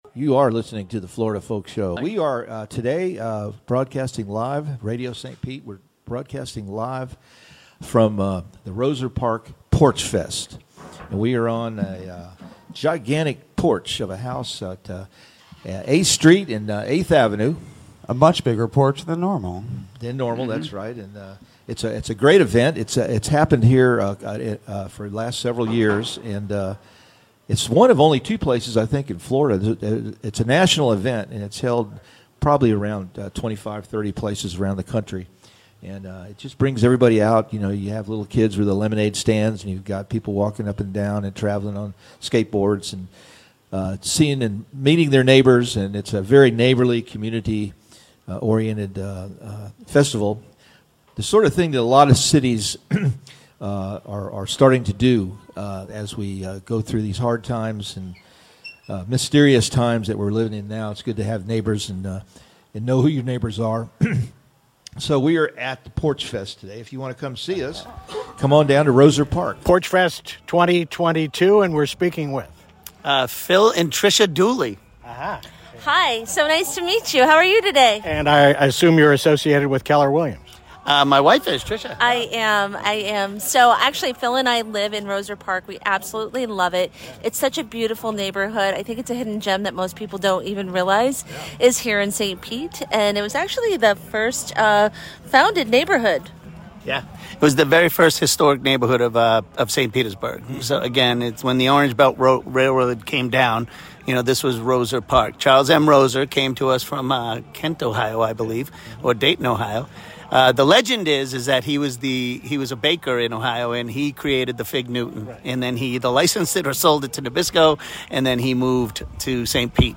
Excerpts of RadioStPete's live coverage of Porchfest 2022 in one of St. Pete's most unique, beautiful and historic neighborhoods.